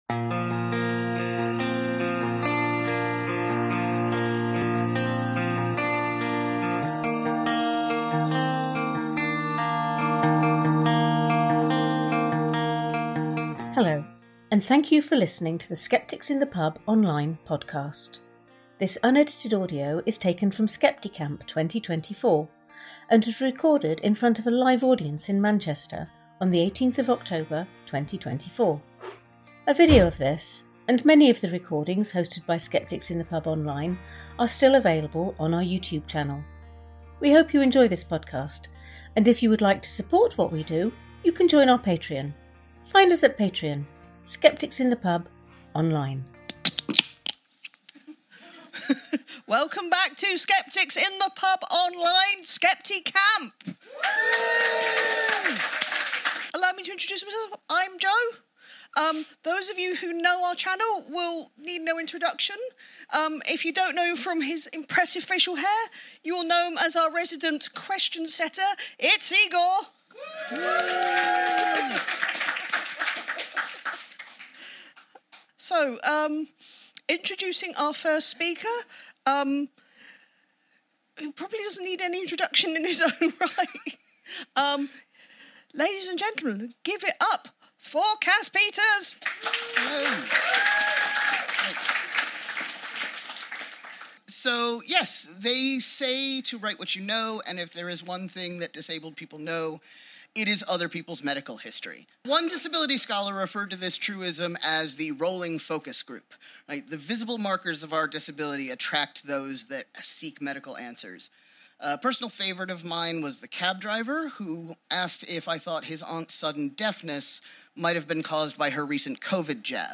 This is the podcast version of the Skeptics in the Pub Online live-streamed talks.